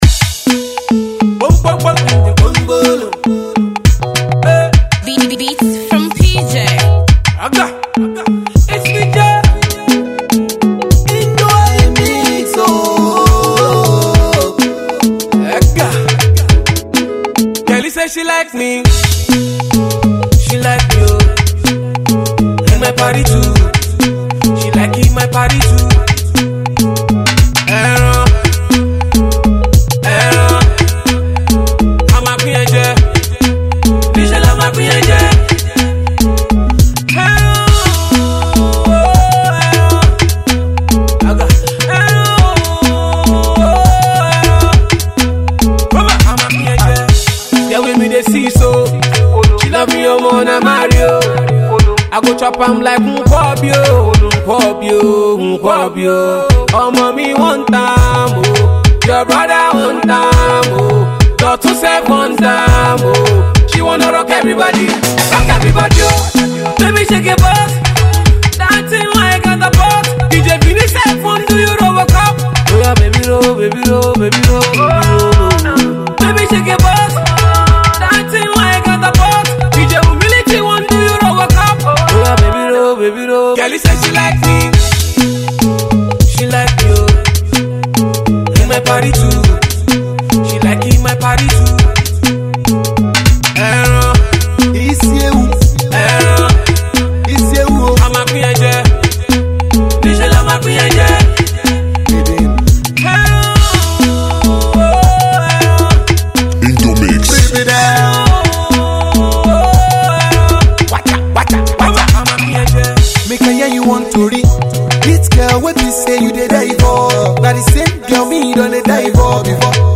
Pop single